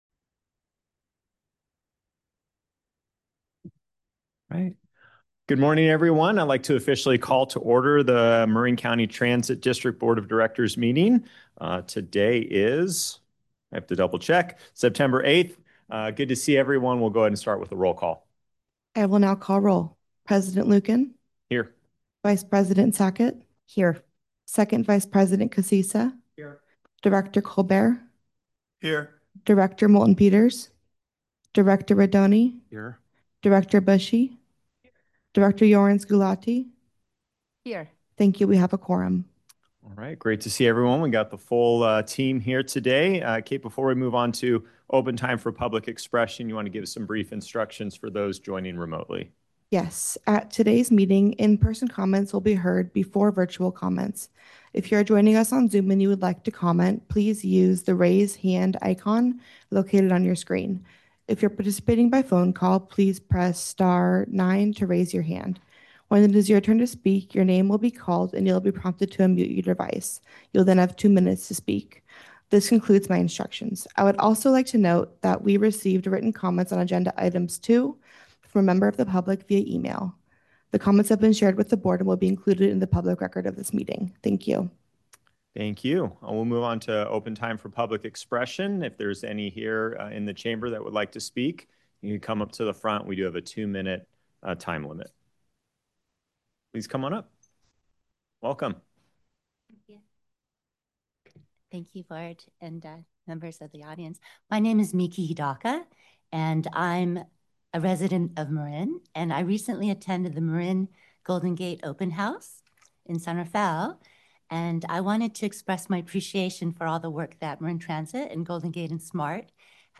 Members of the public can provide comment during open time and on each agenda item when the Board President calls for public comment. In-person comments will be heard before virtual comments.